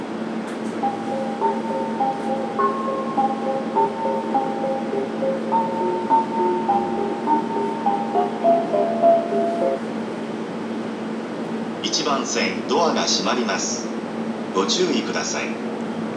白岡駅　Shiraoka Station ◆スピーカー：ユニペックス小型
1番線発車メロディー